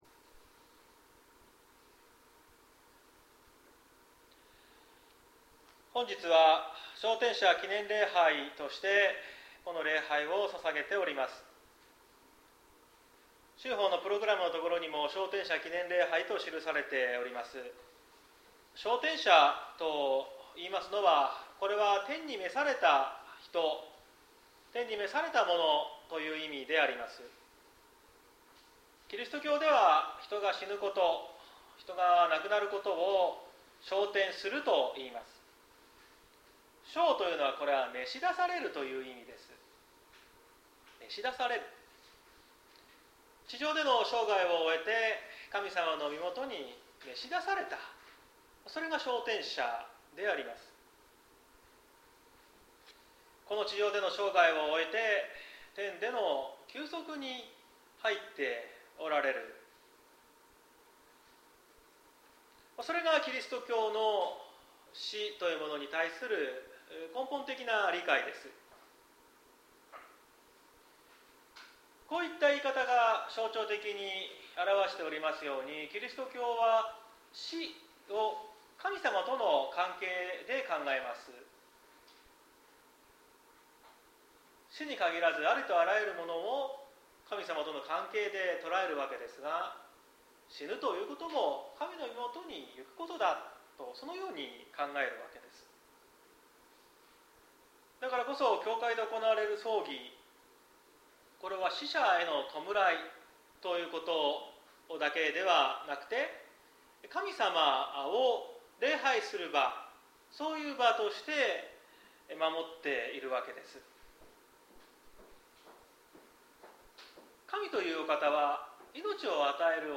2022年10月16日朝の礼拝「死ぬことの意味」綱島教会
説教アーカイブ。